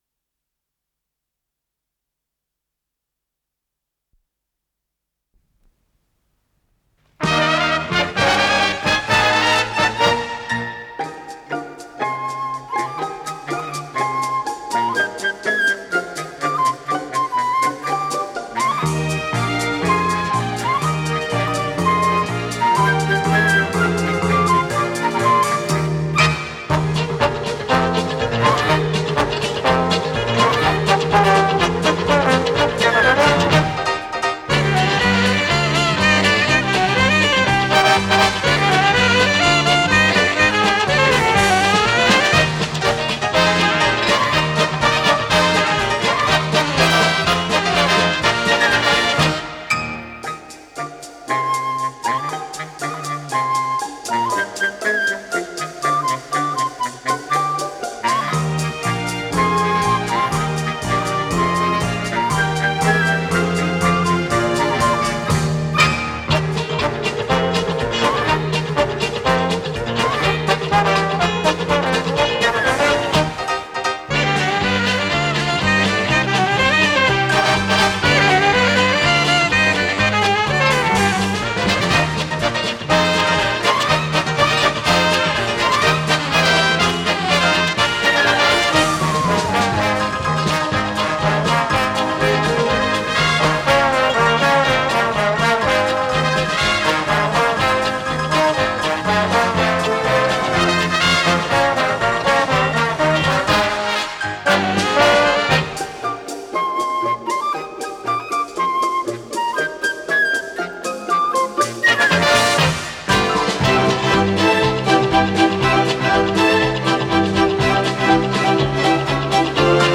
ПодзаголовокПьеса для эстрадного оркестра, ми бемоль мажор